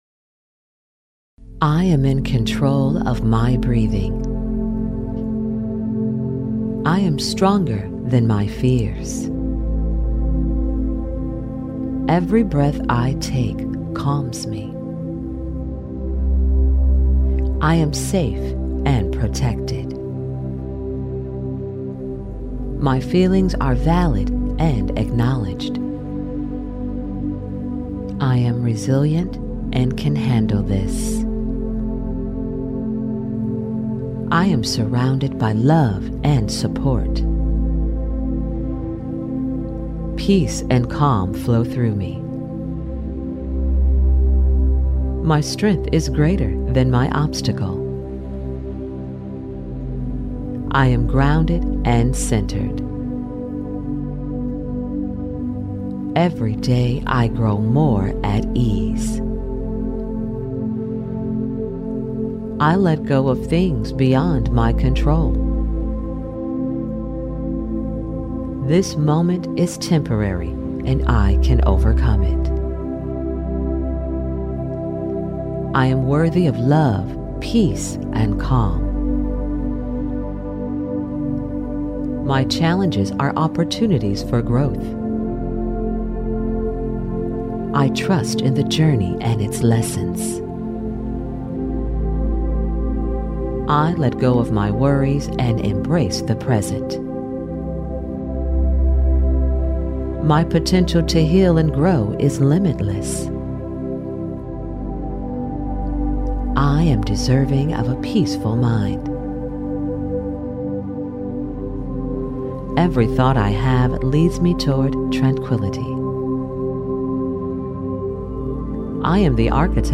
Female
English (North American)
Adult (30-50)
Audiobooks
Affirmations
1023ACX_calming_affirmations_retail.mp3